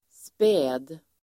Uttal: [spä:d]